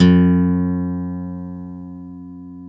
GTR 6-STR20T.wav